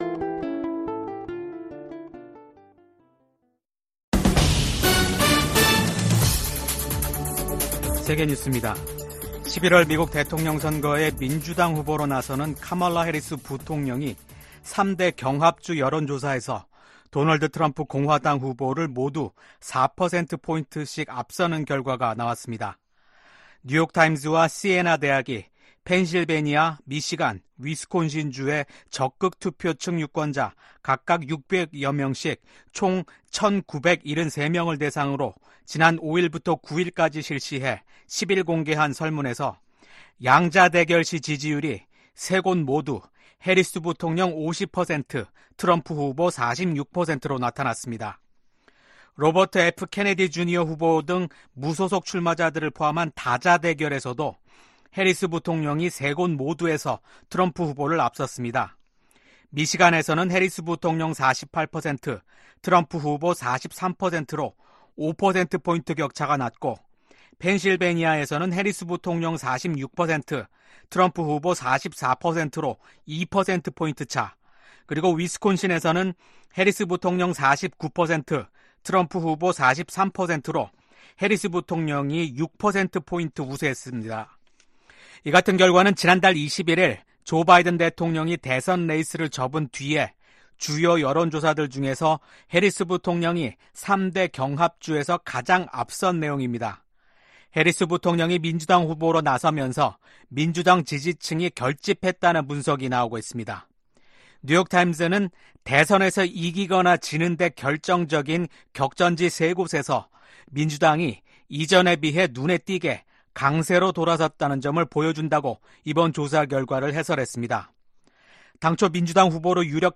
VOA 한국어 아침 뉴스 프로그램 '워싱턴 뉴스 광장' 2024년 8월 13일 방송입니다. 미국 국무부는 제재는 긴장만 고조시킨다는 중국의 주장에 새로운 대북제재 감시 체계 마련의 필요성을 지적했습니다. 한국 군 합동참모본부와 미한연합사령부는 오늘(12일) 국방부 청사에서 열린 공동 기자회견에서 올 하반기 미한 연합훈련인 ‘을지프리덤실드’(UFS) 연습을 이달 19일부터 29일까지 실시한다고 발표했습니다.